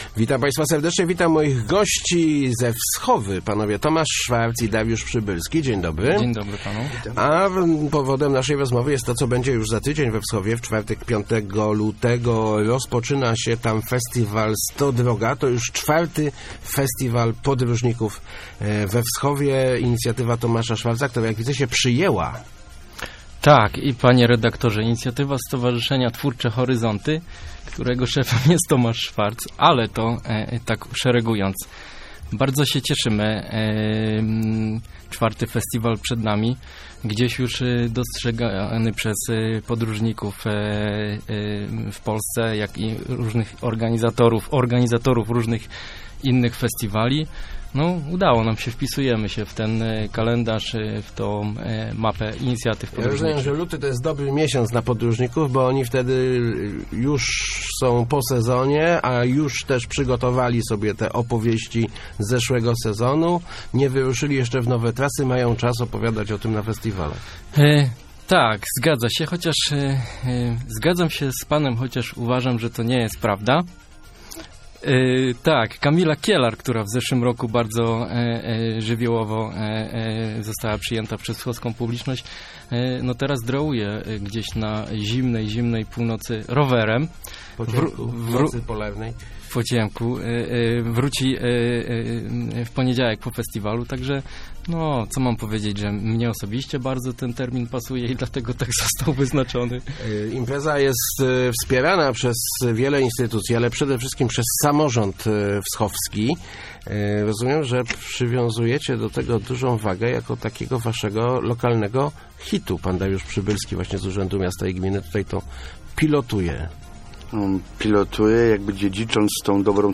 Start arrow Rozmowy Elki arrow Podróżnicy znów we Wschowie